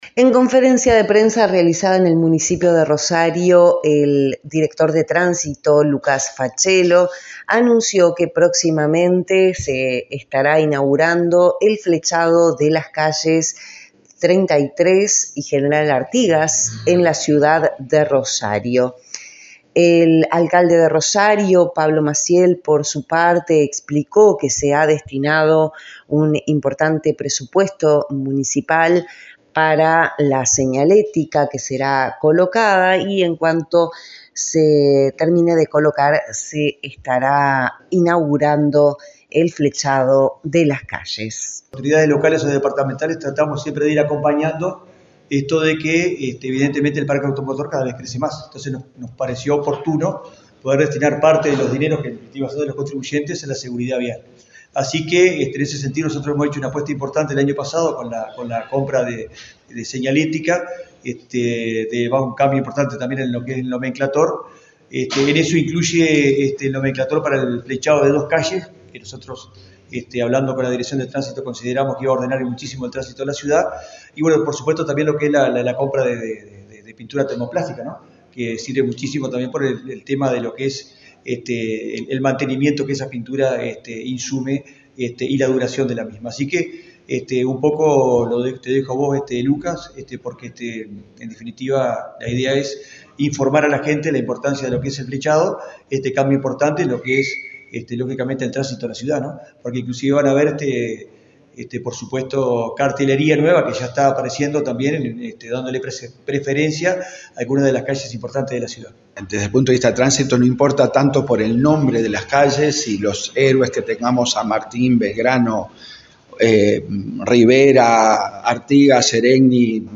El Director de Tránsito Lucas Facello en una conferencia de prensa realizada en el municipio de Rosario, junto al alcalde Pablo Maciel, anunció que próximamente será inaugurado el flechado de las calles Treinta y Tres y General Artigas de la ciudad de Rosario.